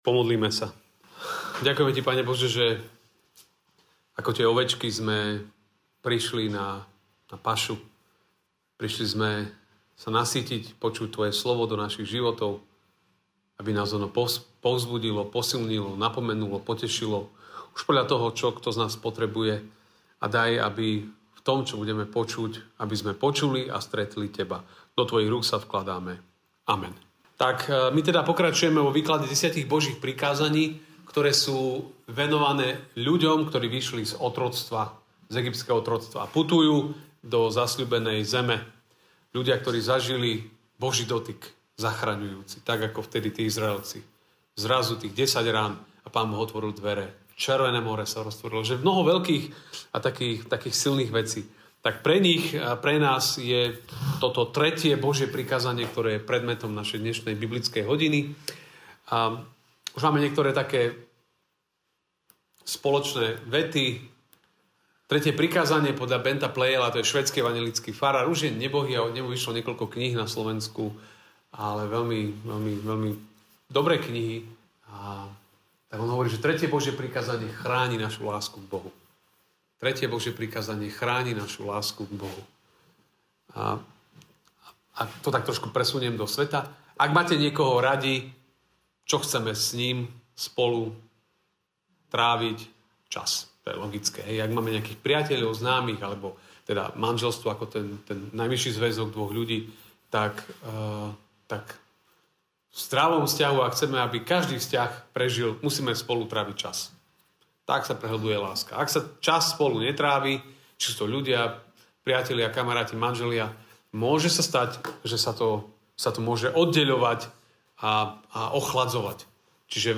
Božie prikázanie MP3 SUBSCRIBE on iTunes(Podcast) Notes Sermons in this Series 2.